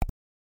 click1.mp3